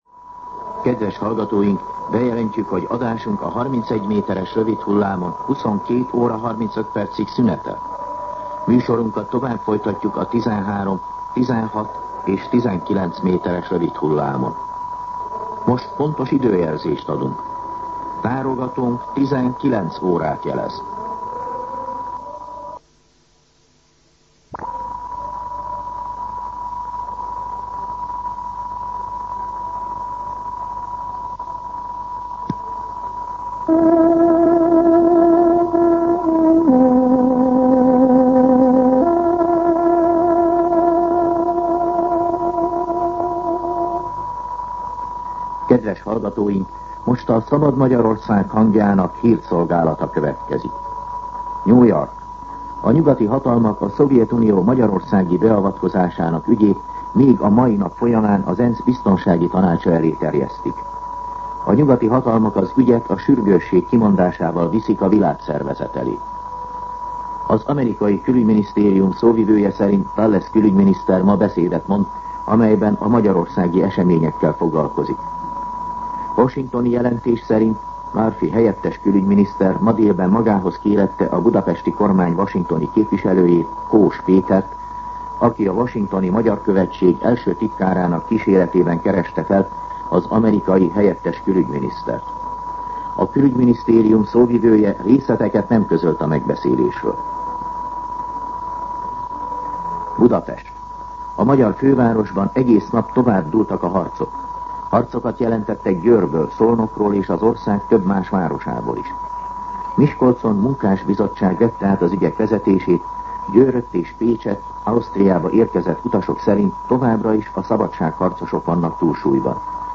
19:00 óra. Hírszolgálat